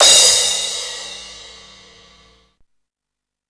CRASH10   -L.wav